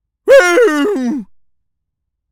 seal_walrus_death_02.wav